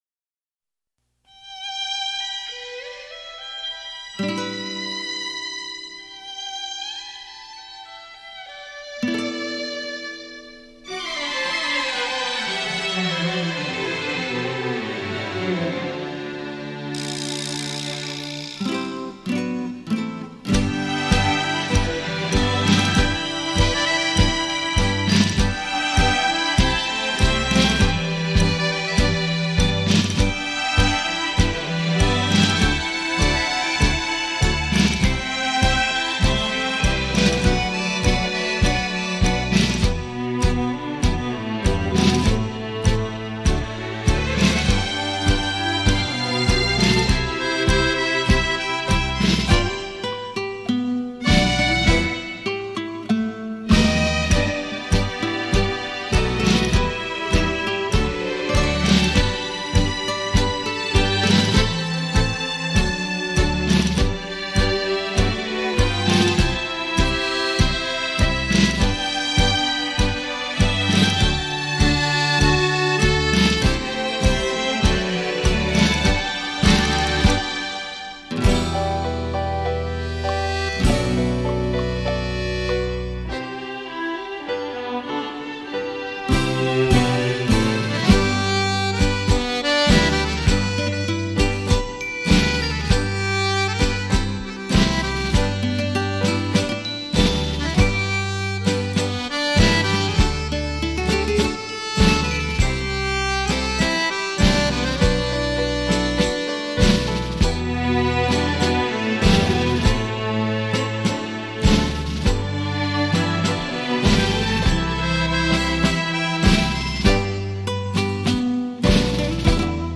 专辑语言：纯音